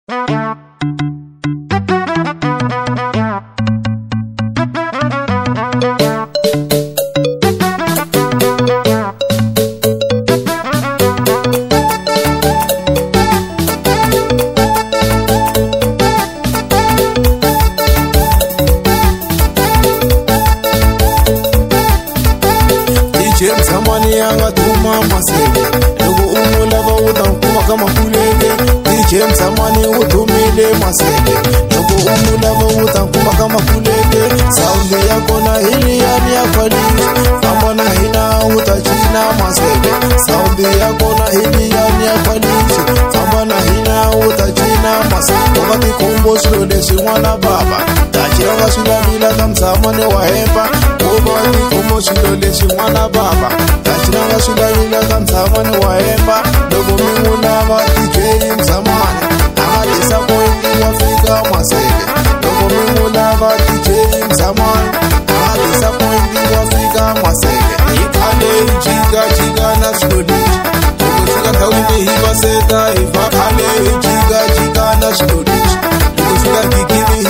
01:17 Genre : Xitsonga Size